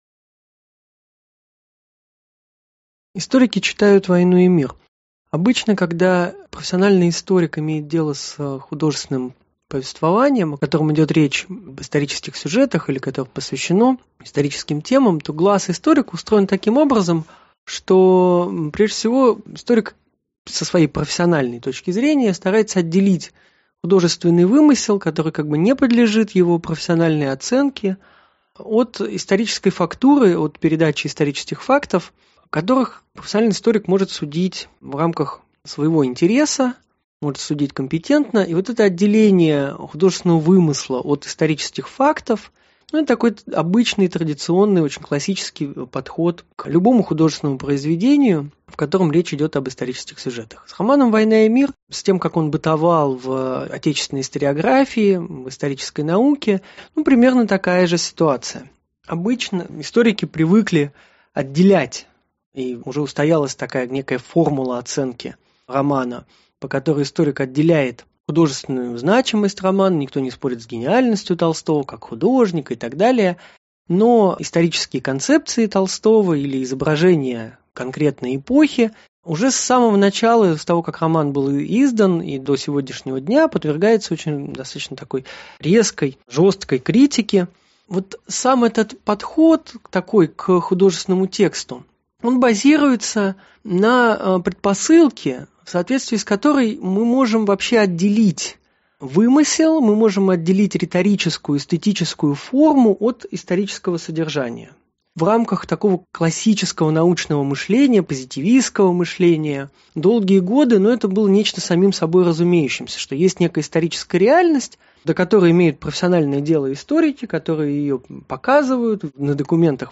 Аудиокнига Историки читают «Войну и мир».
Прослушать и бесплатно скачать фрагмент аудиокниги